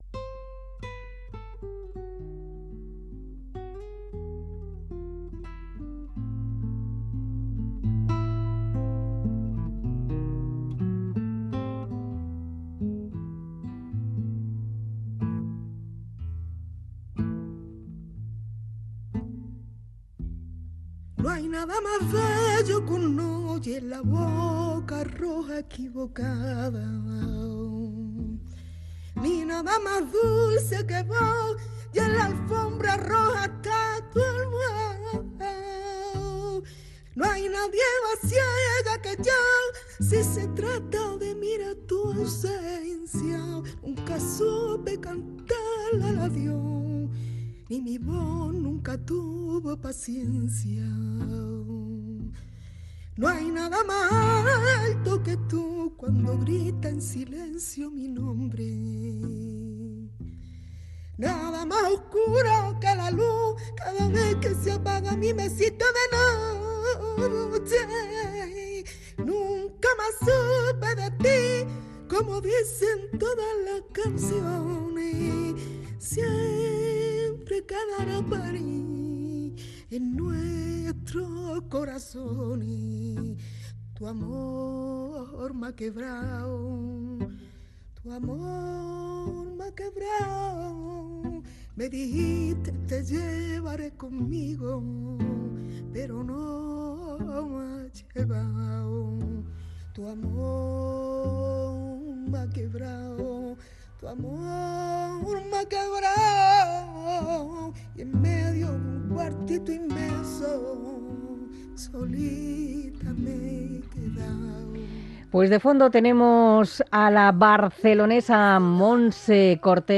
Charlamos con el percusionista